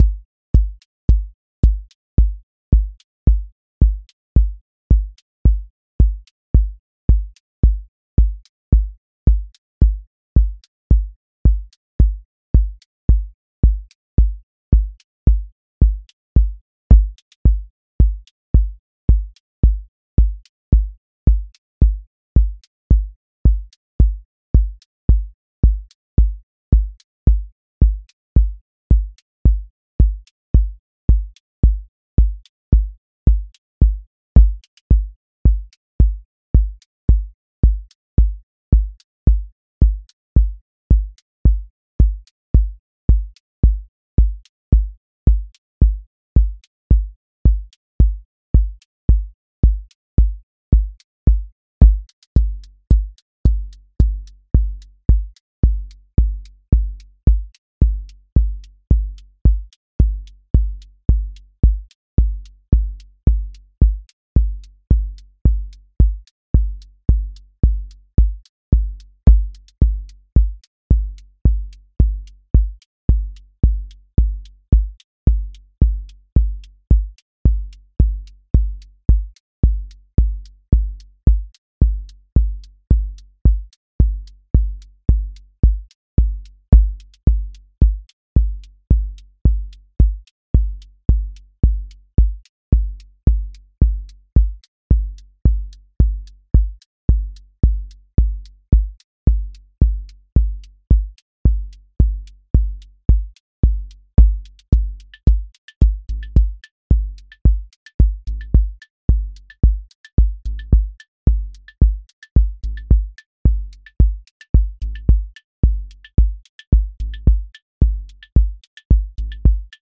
QA Listening Test house Template: four_on_floor
120-second house song with grounded sub, counter motion, a bridge lift, and a clear return
• voice_kick_808
• voice_hat_rimshot
• voice_sub_pulse
• tone_warm_body